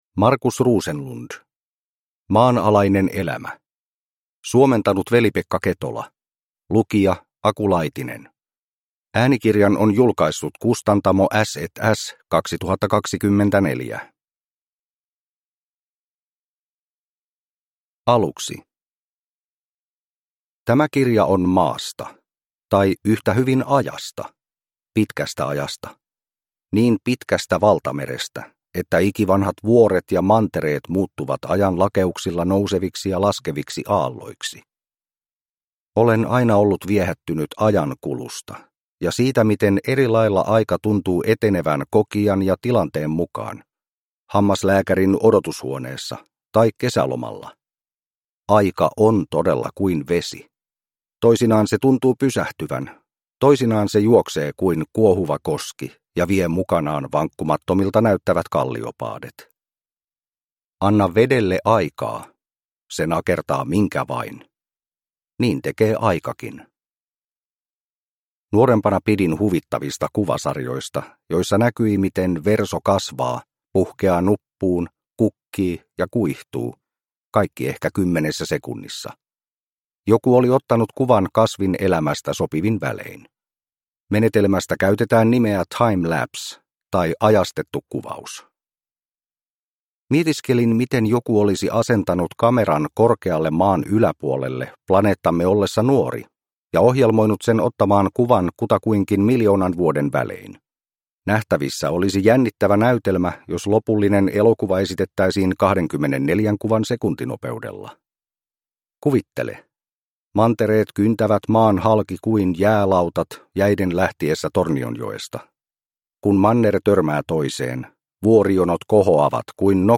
Maanalainen elämä – Ljudbok